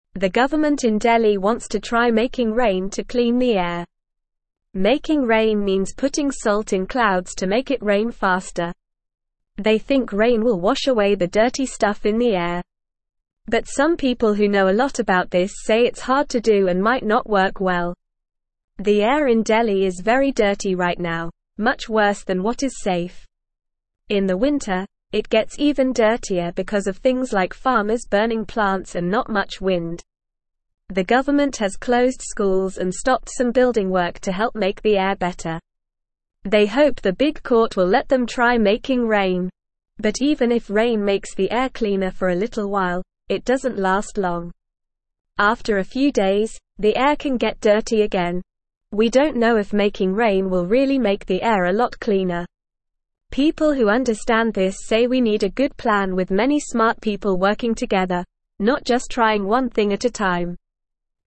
Normal
English-Newsroom-Lower-Intermediate-NORMAL-Reading-Making-Rain-to-Clean-Delhis-Dirty-Air.mp3